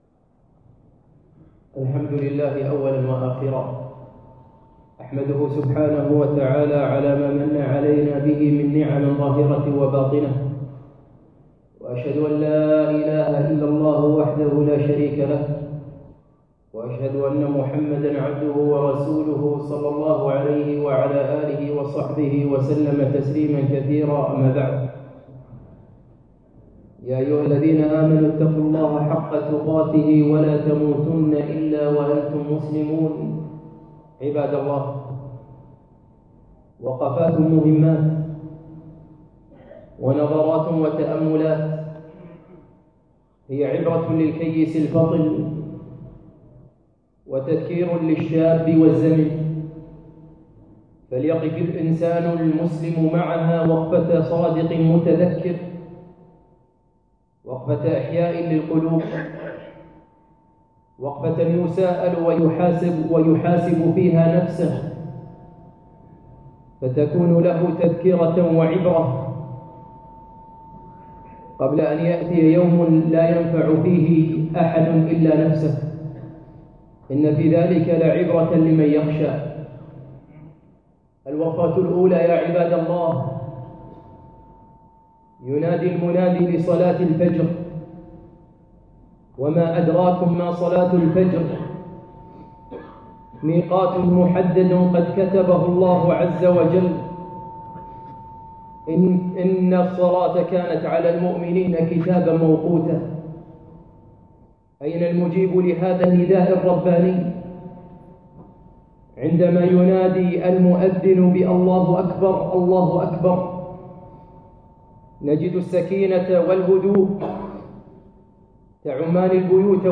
خطبة - وقفات للعبرة